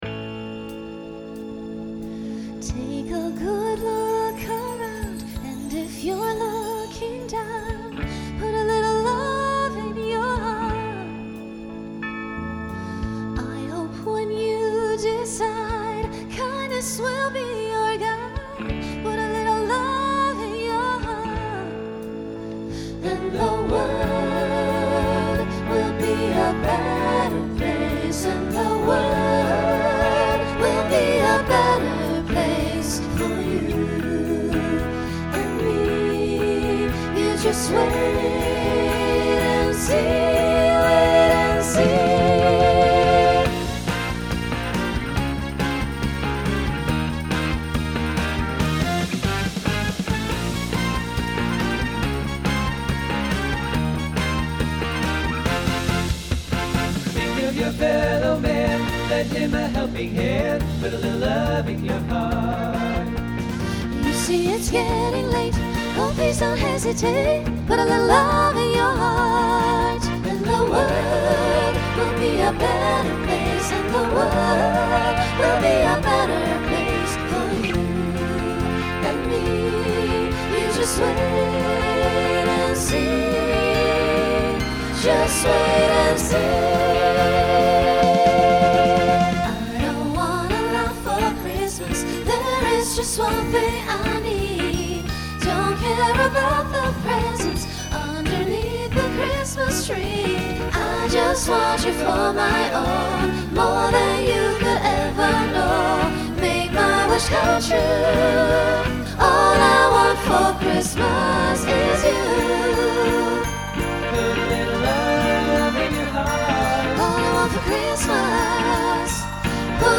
Genre Holiday , Pop/Dance
Voicing SATB